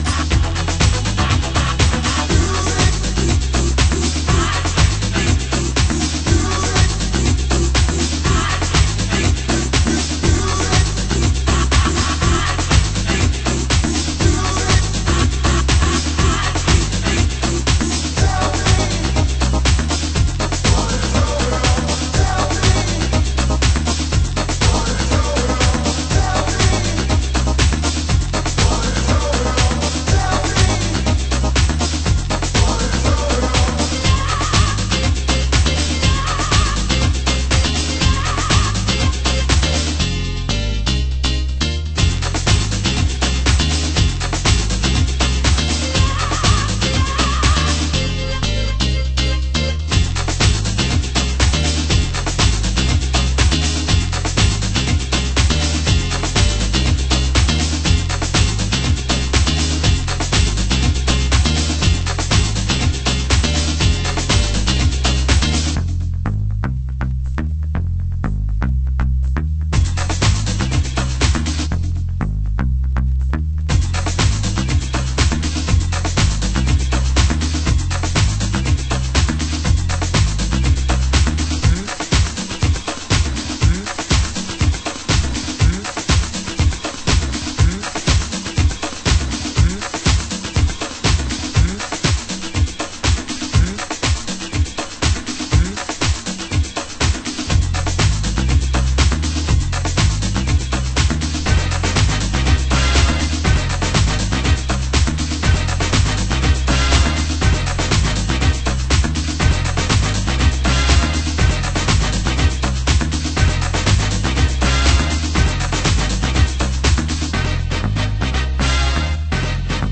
★90'S HOUSE LABEL